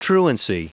Prononciation du mot truancy en anglais (fichier audio)
Prononciation du mot : truancy